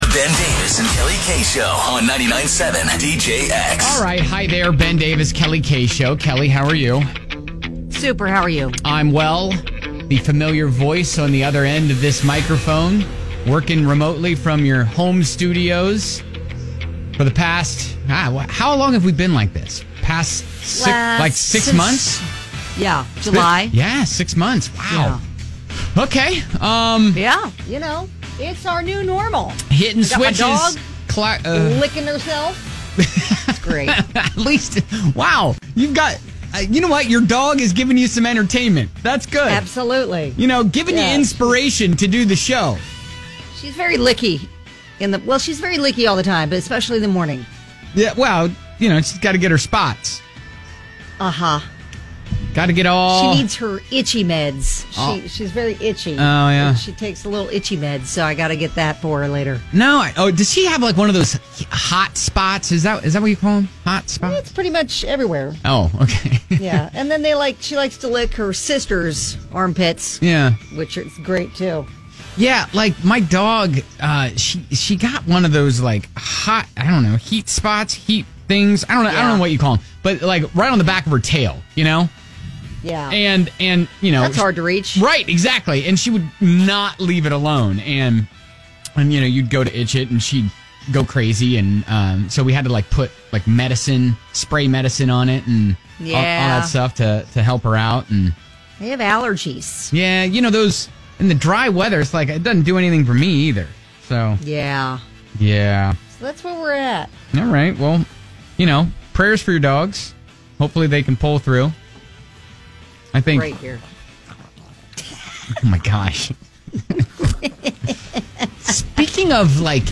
Got a call from a listener who needed advice on her living situation...she was watching her brother's marriage deteriorate and needs advice on if she should step in. We talk expensive mistakes inspired by a guy that can't remember his password to access MILLIONS in Bitcoin.